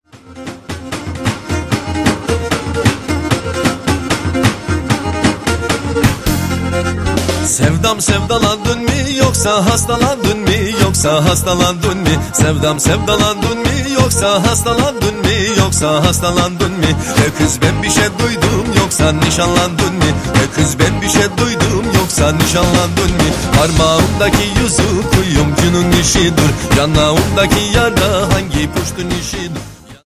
Karadeniz ezgilerinin güçlü sesi